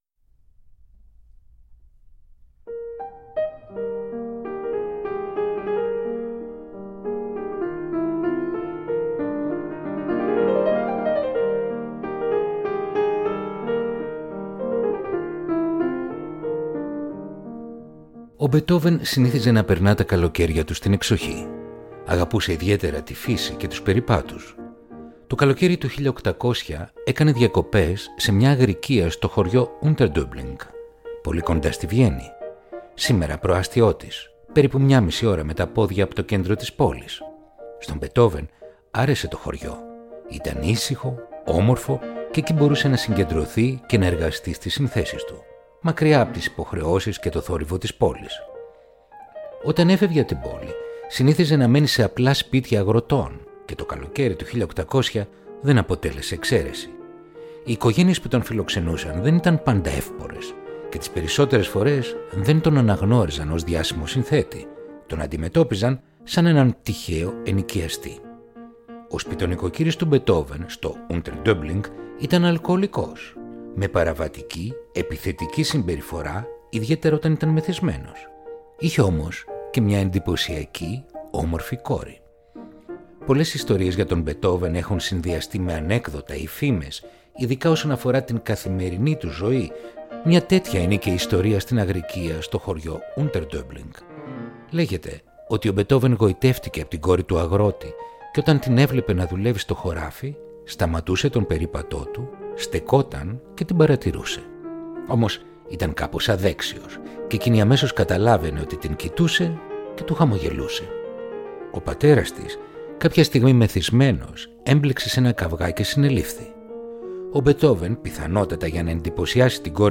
Ludwig van Beethoven Κοντσέρτο για Πιάνο Αρ. 3 σε Ντο Ελάσσονα